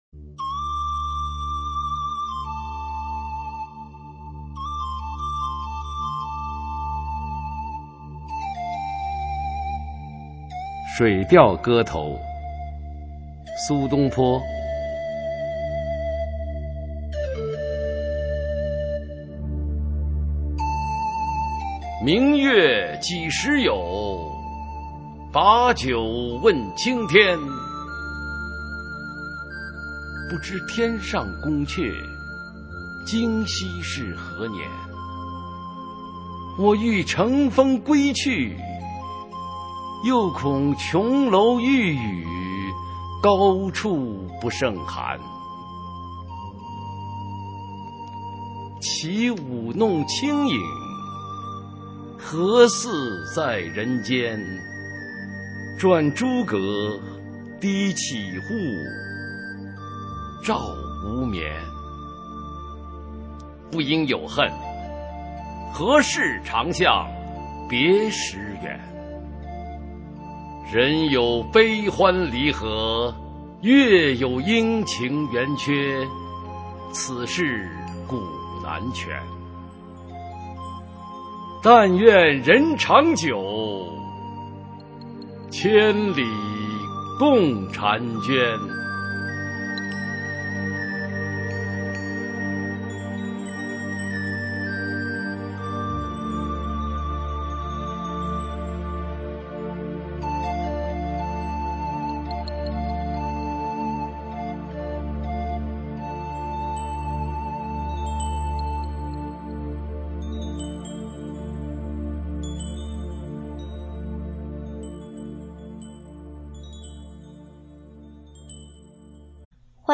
首页 视听 学说普通话 美声欣赏
普通话美声欣赏：水调歌头